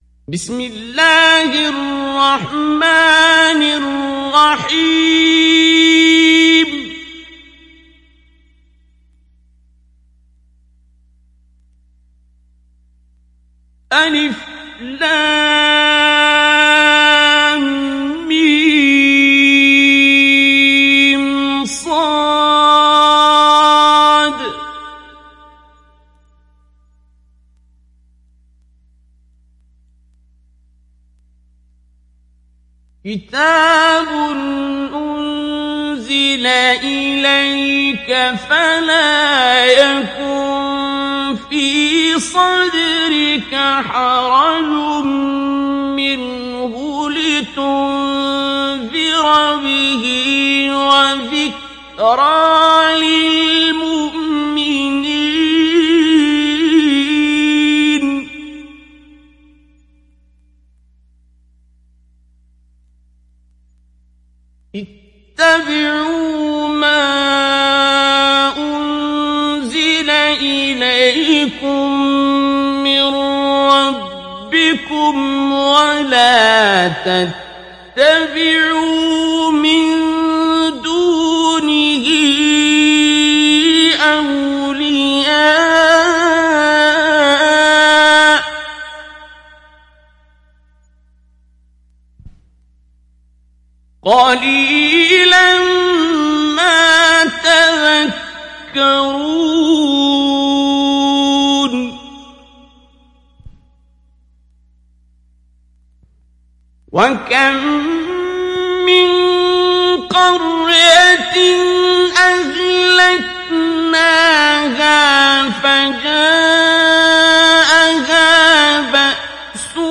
Araf Suresi İndir mp3 Abdul Basit Abd Alsamad Mujawwad Riwayat Hafs an Asim, Kurani indirin ve mp3 tam doğrudan bağlantılar dinle
İndir Araf Suresi Abdul Basit Abd Alsamad Mujawwad